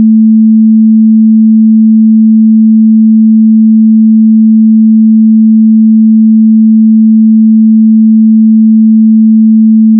sin220m.wav